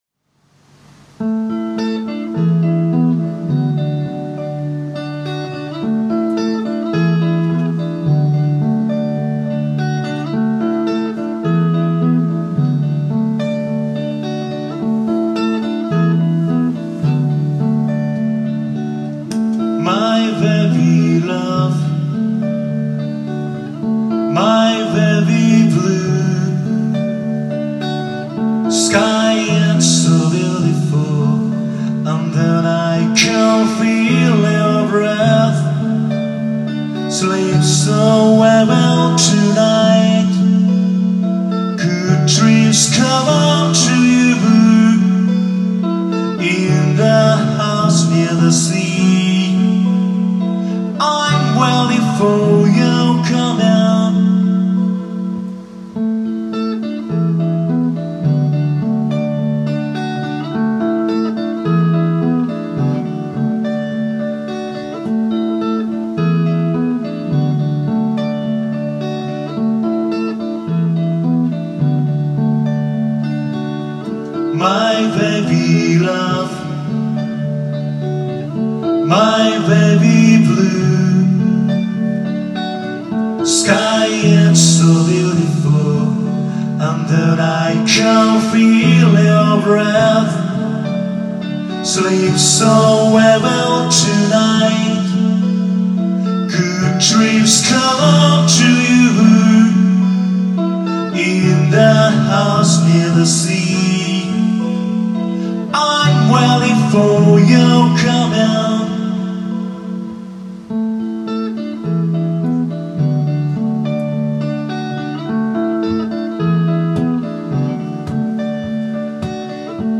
※ラウン試聴音源
☆2025.7.2 [WED] at lown, 下北沢
弾き語りワンマン
E.guitar